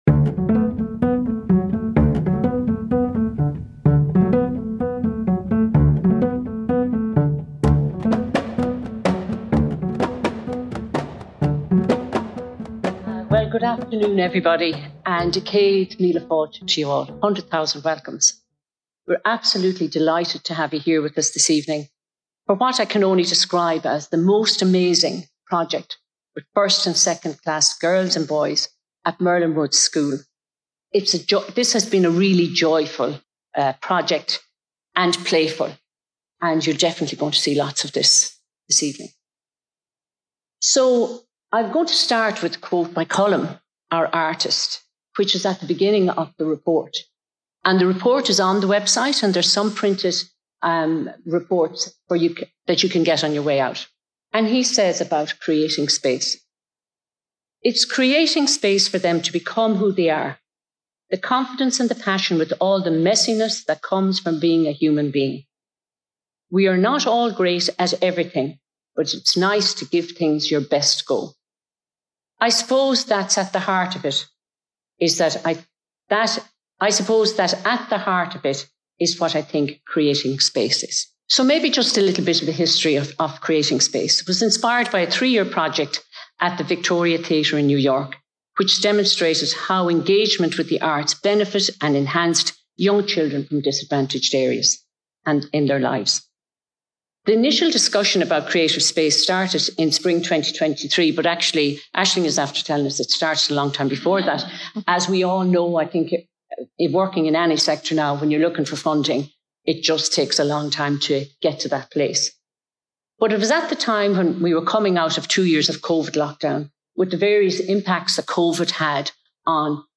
This panel of stakeholders reflect on this child-led project and share insights for developing meaningful arts experiences in the classroom.